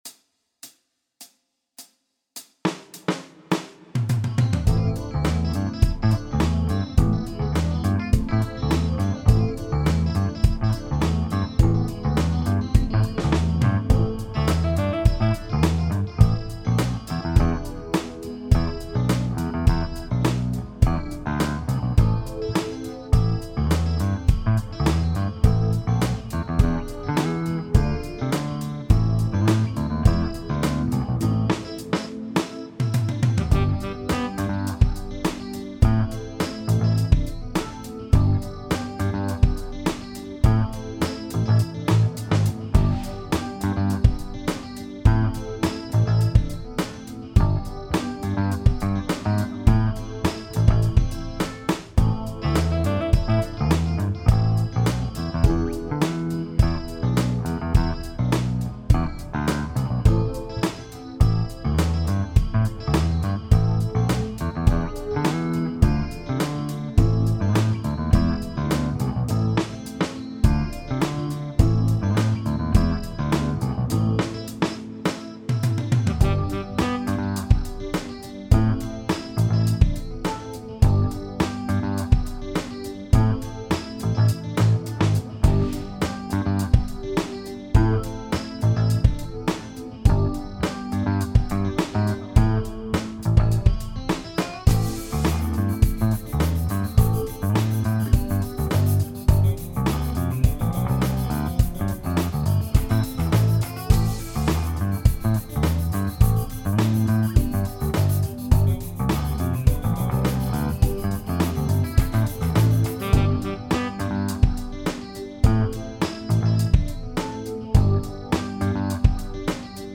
Bass Guitar
For Bass Guitar.
great bass grooves
World-Music style